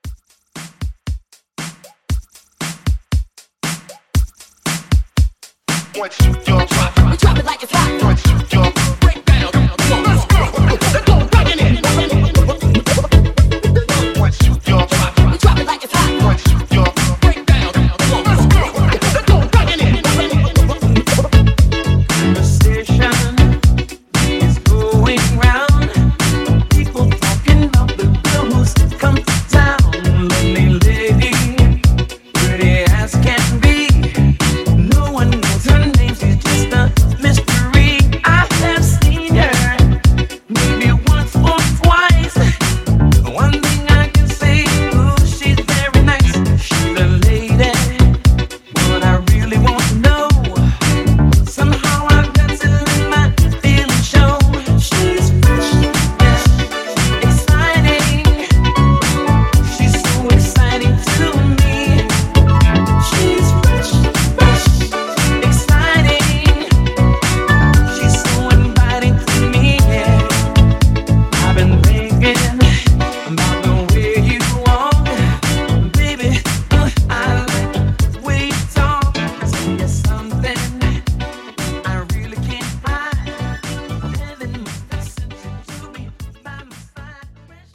Genre: BOOTLEG
Clean BPM: 105 Time